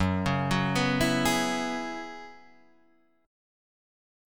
Gb9sus4 chord